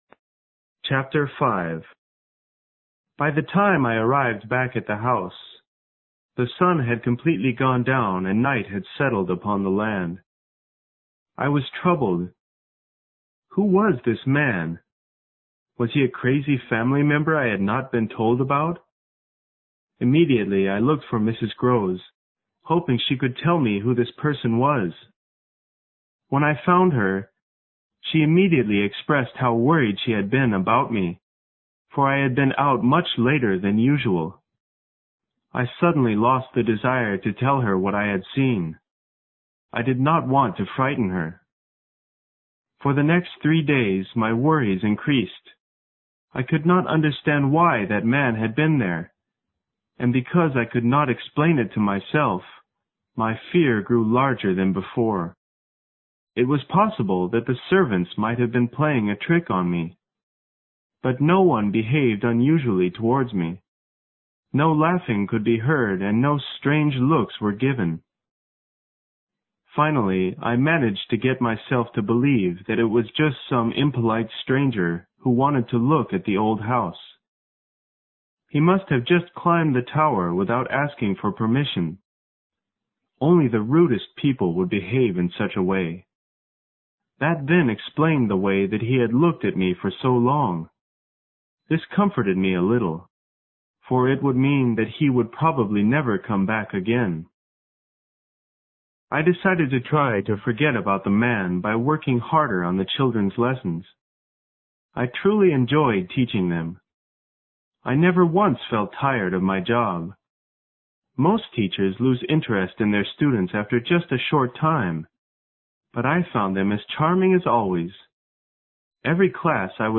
有声名著之螺丝在拧紧chapter5 听力文件下载—在线英语听力室